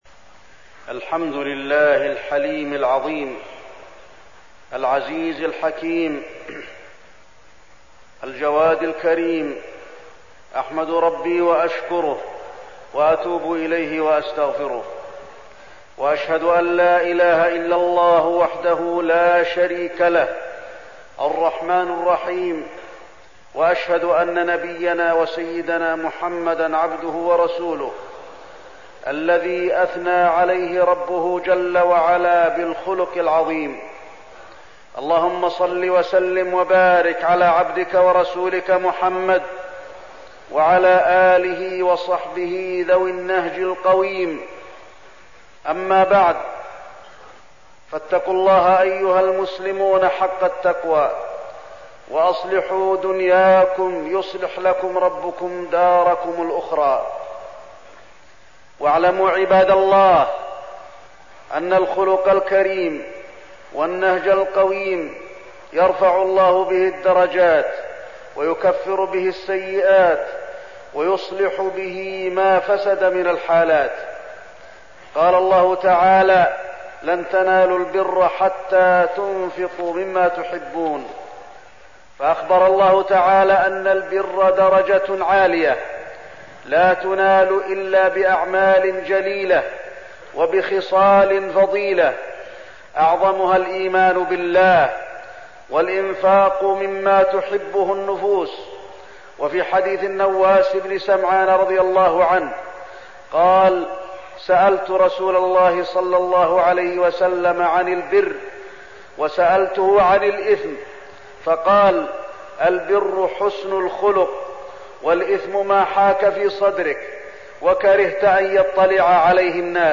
تاريخ النشر ٢٨ ربيع الأول ١٤١٨ هـ المكان: المسجد النبوي الشيخ: فضيلة الشيخ د. علي بن عبدالرحمن الحذيفي فضيلة الشيخ د. علي بن عبدالرحمن الحذيفي الأخلاق الإسلامية